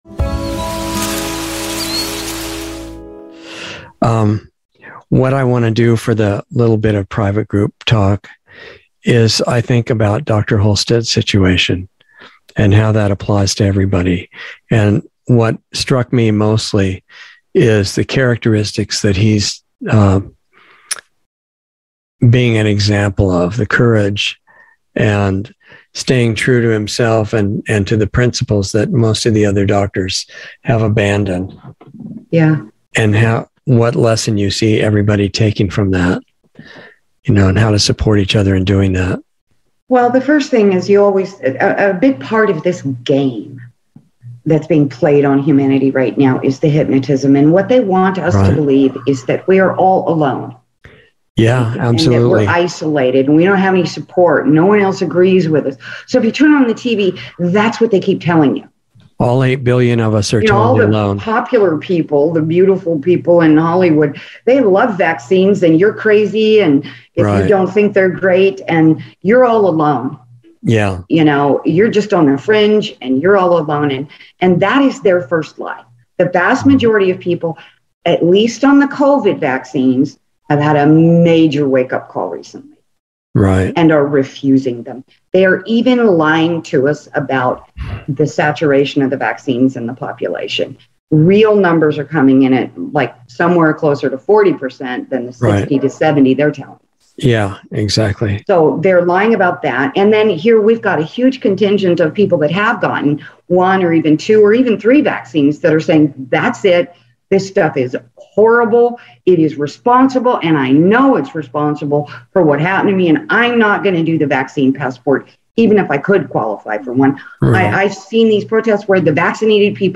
Insider Interview 3/24/22